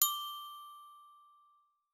Ding.ogg